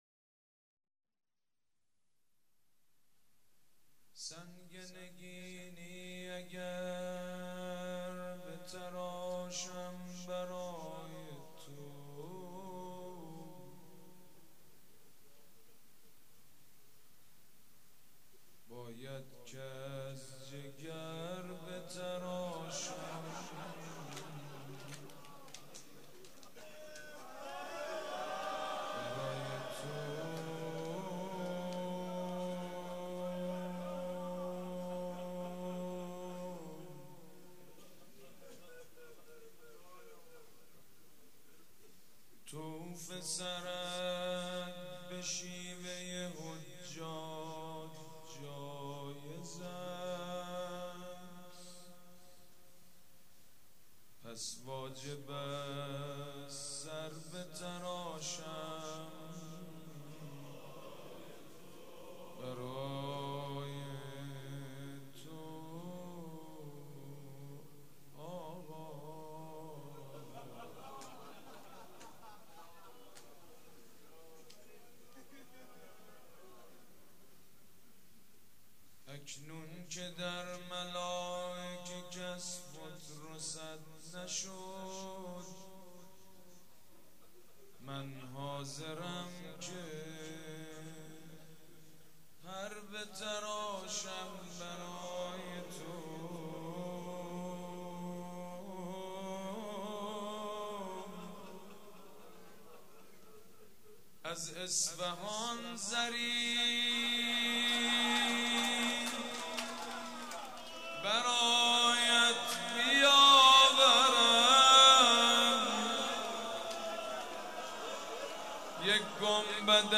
روضه
روضه شب پنجم مراسم عزاداری صفر